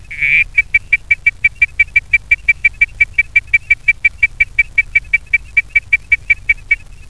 Identify the following calls of frogs.
Frog 1;